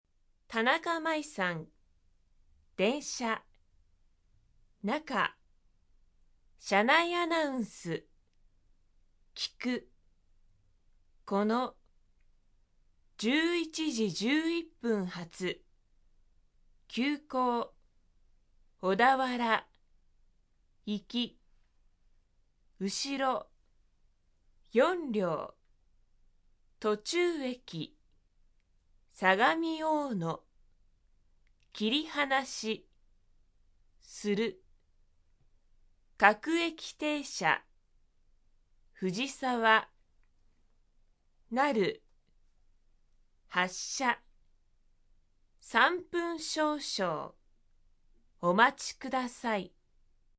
車内